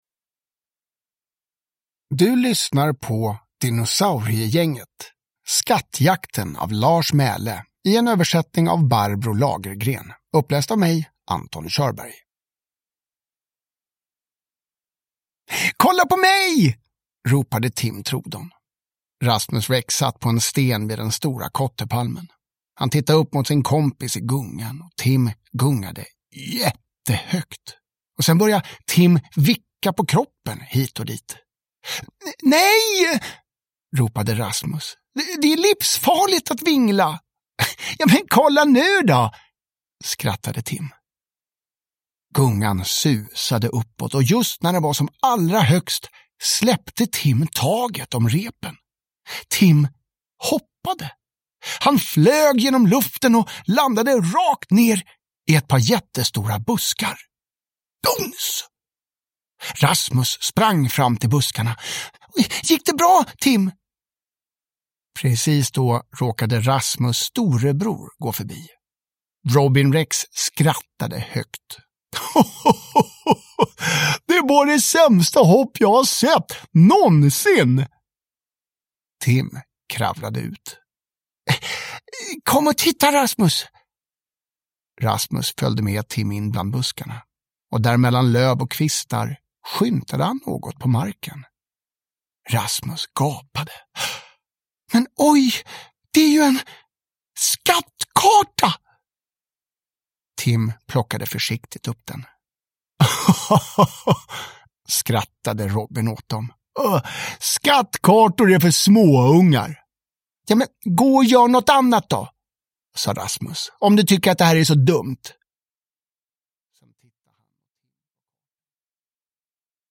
Skattjakten (ljudbok) av Lars Mæhle